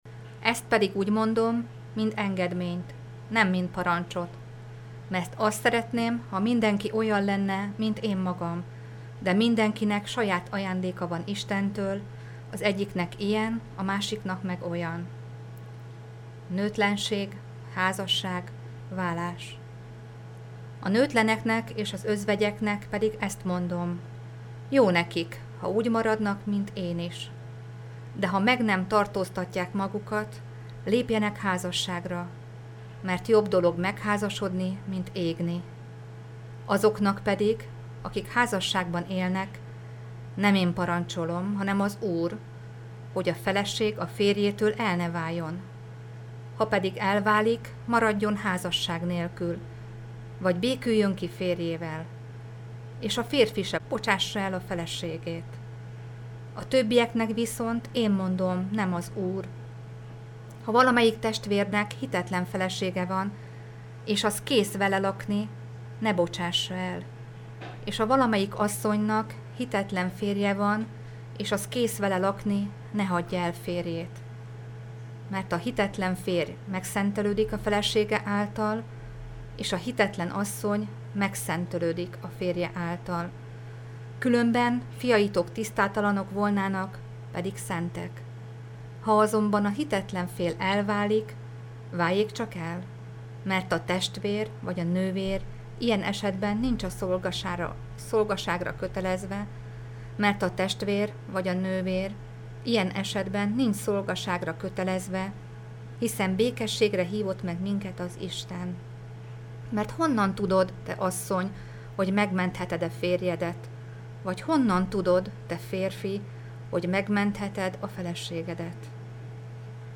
A felvétel a Helikon Rádió nagykanizsai stúdiójában készült 2016 júliusában-augusztusában.